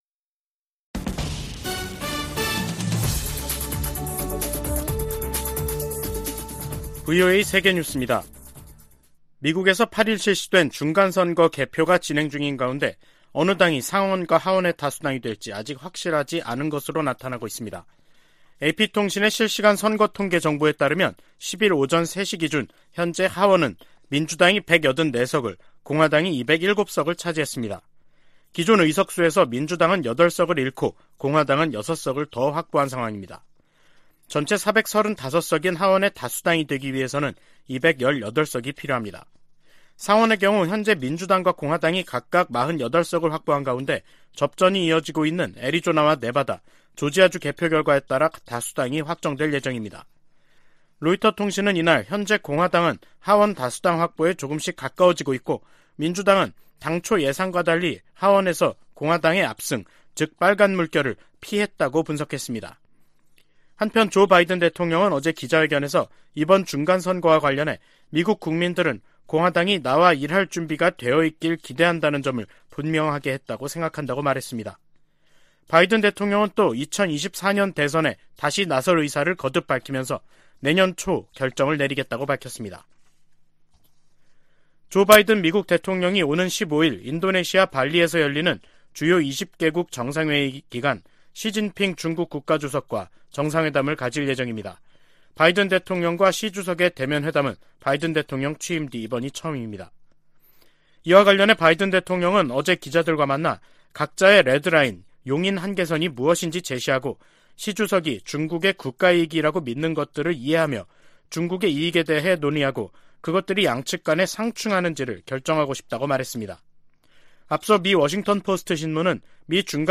VOA 한국어 간판 뉴스 프로그램 '뉴스 투데이', 2022년 11월 10일 3부 방송입니다. 미국 중간선거 결과 의회 다수당의 변화가 예상되는 가운데 한반도 등 대외 현안들에 어떤 영향을 미칠지 주목됩니다. 미국과 한국, 일본, 중국의 정상들이 G20 정상회의에 참석하면서 북한 문제를 둘러싼 다자 외교전이 펼쳐질 전망입니다. 북한이 핵물질 생산을 위해 영변 핵시설을 지속적으로 가동하고 있다고 전 국제원자력기구(IAEA) 사무차장이 밝혔습니다.